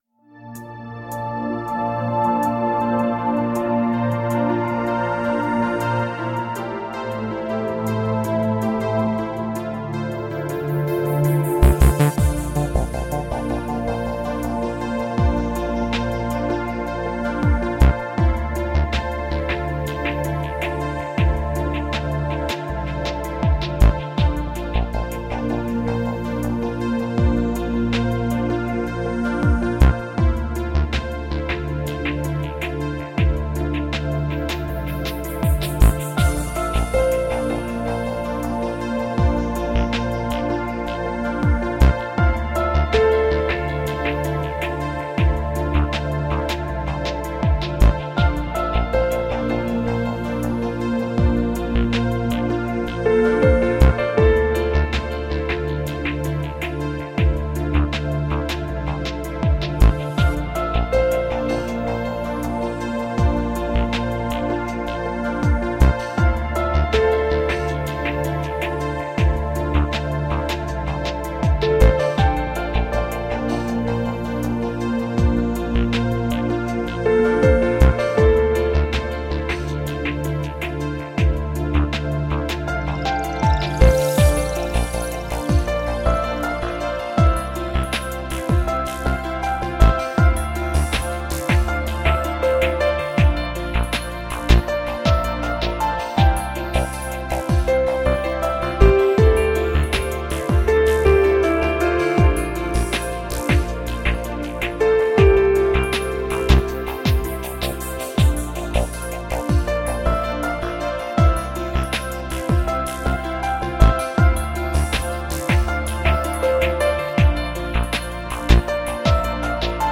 深远、飘渺、纯净，就好比在寂静的海滩，夜晚仰望星空，梦一般的旋律令人无比放松。
其音乐风格以电子音乐为主，并且广为融合民族音乐的元素。